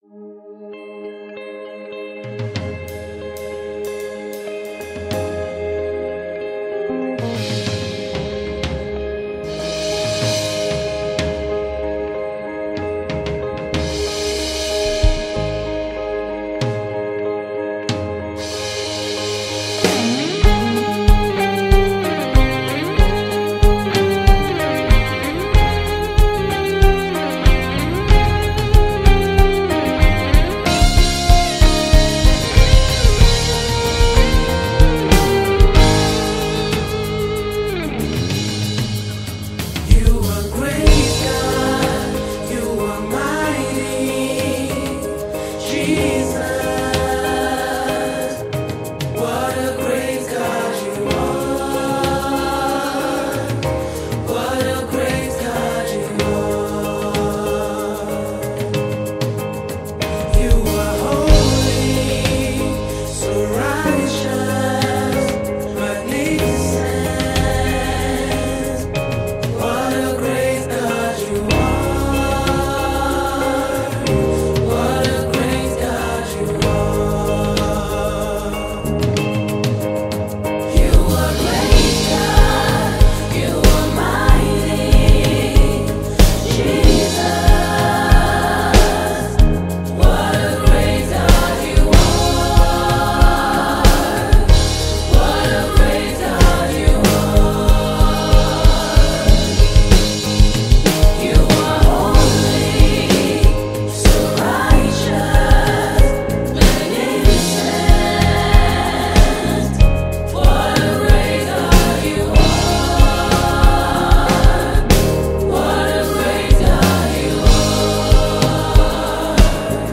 February 11, 2025 Publisher 01 Gospel 0